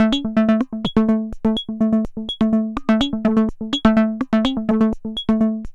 tx_synth_125_aroundabout_A.wav